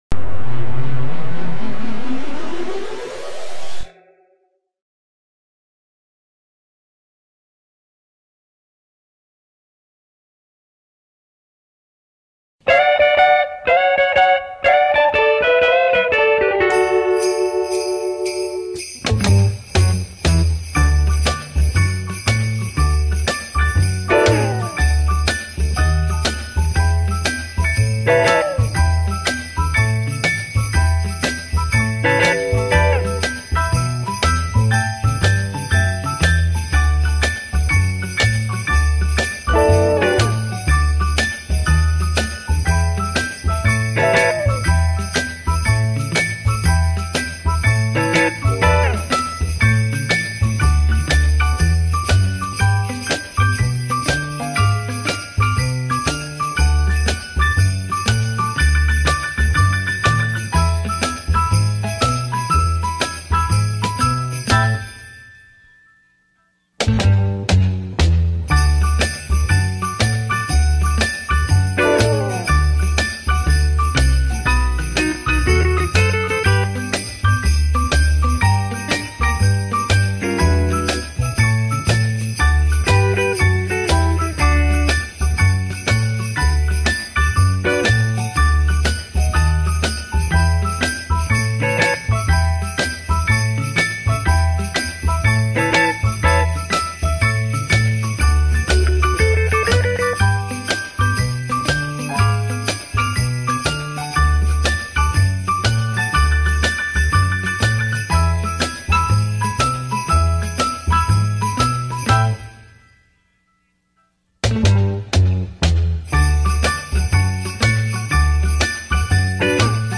Base Instrumental: